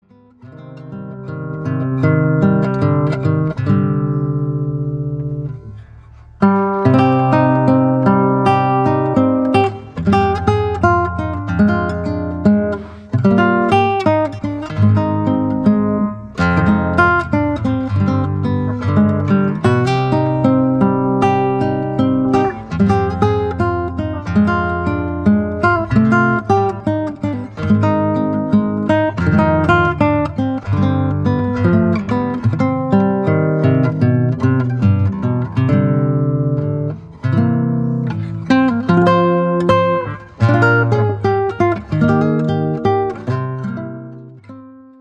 Classical guitar arrangements